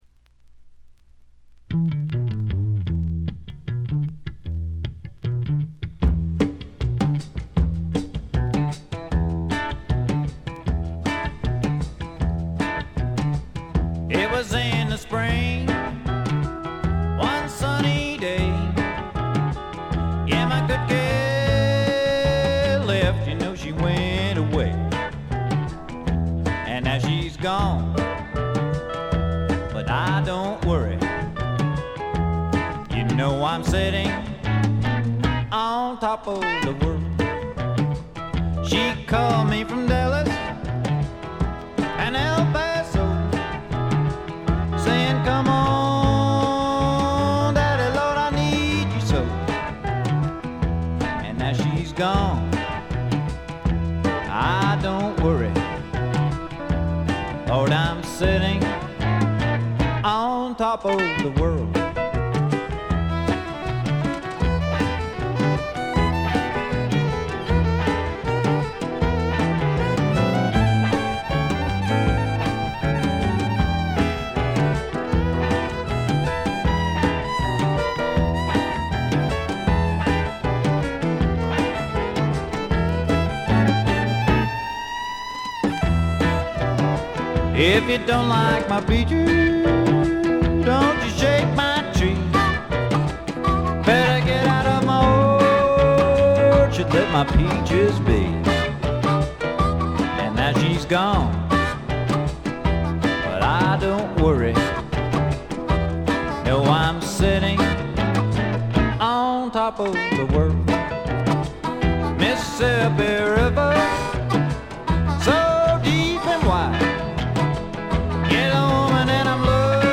これ以外は軽微なチリプチ少々で良好に鑑賞できると思います。
試聴曲は現品からの取り込み音源です。